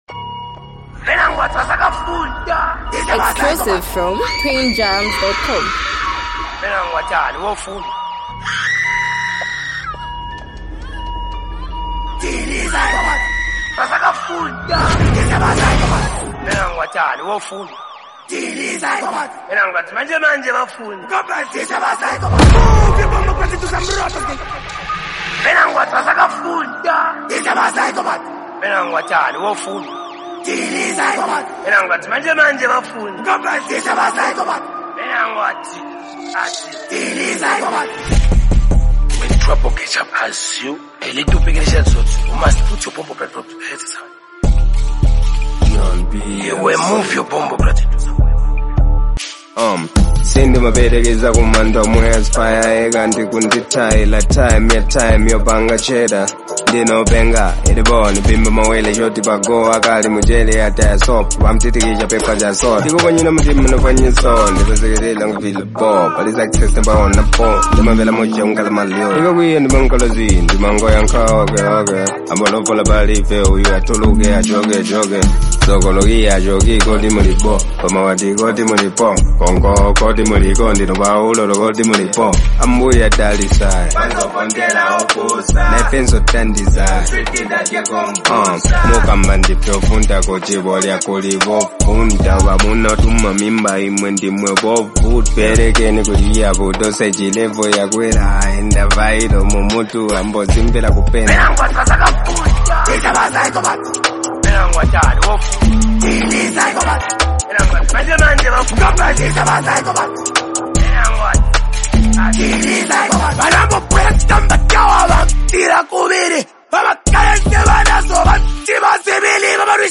Highly multi talented act and super creative rapper